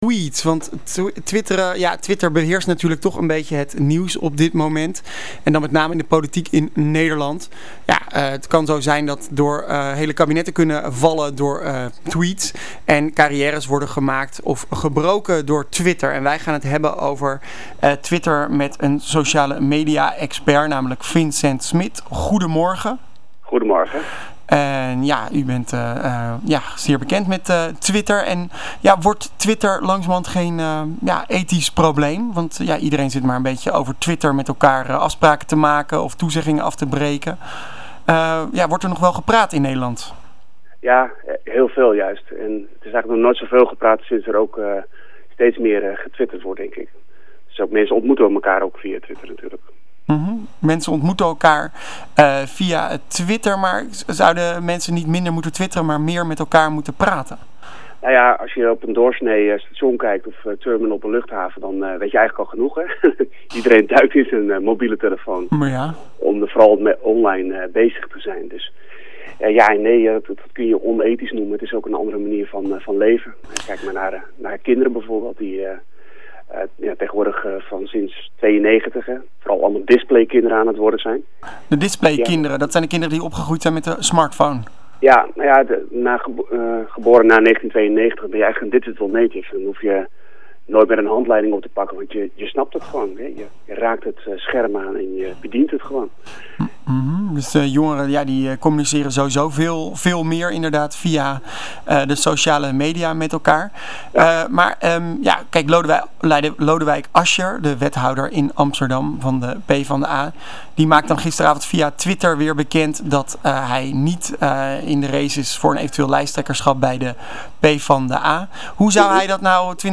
social-media expert en vroegen hem of er nog wel gewoon gepraat wordt in Nederland.